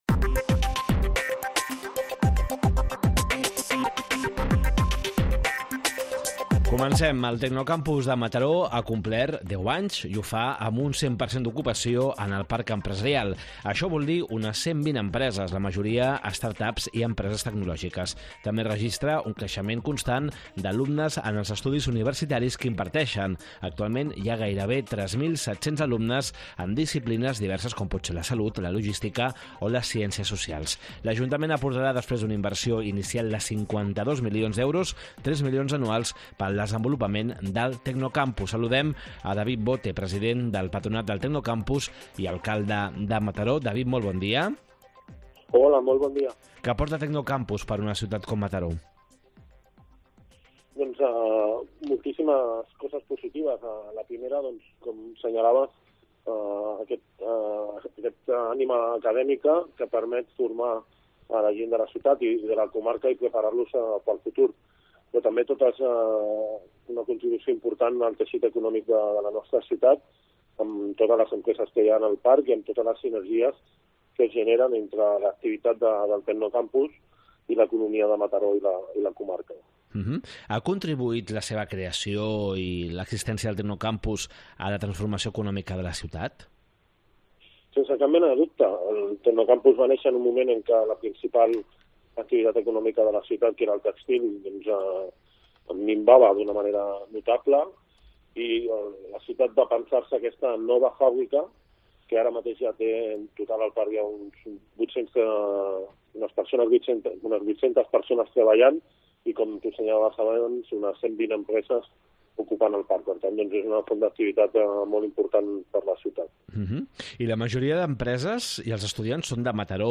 Entrevista David Bote, president del Patronat del Tecnocampus i Alcalde de Mataró